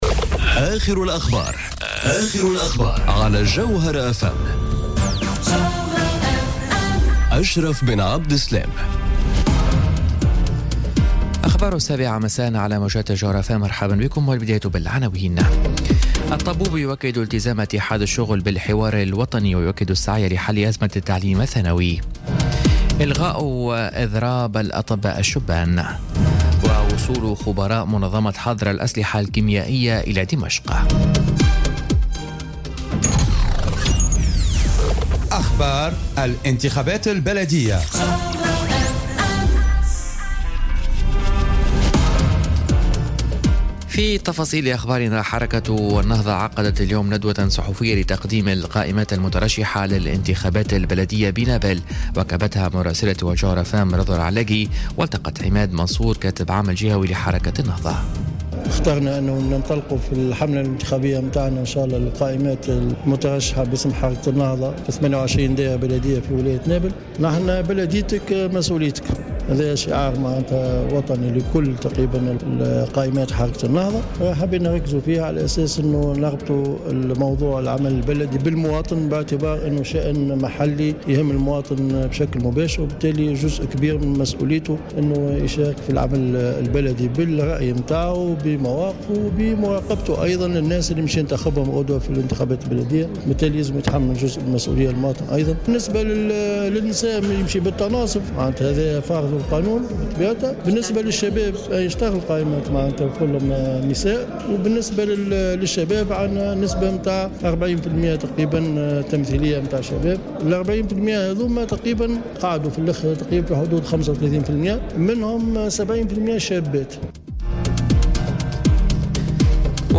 نشرة أخبار السابعة مساءً ليوم السبت 14 أفريل 2018